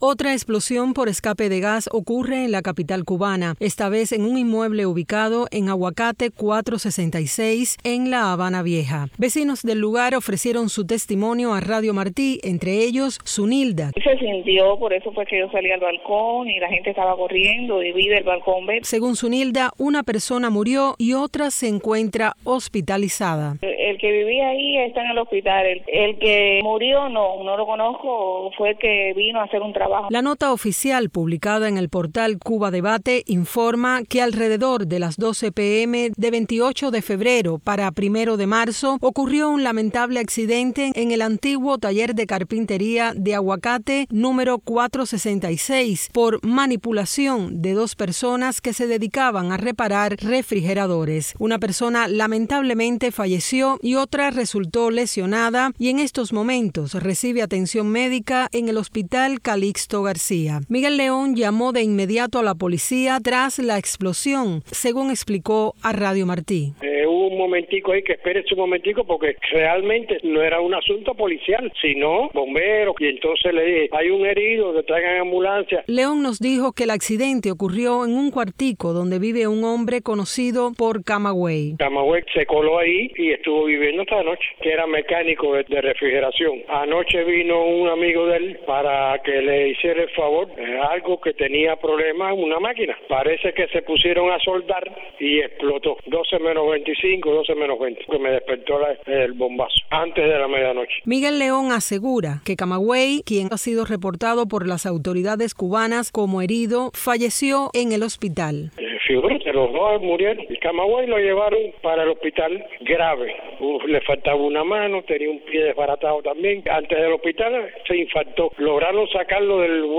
Vecinos del lugar ofrecieron su testimonio a Radio Martí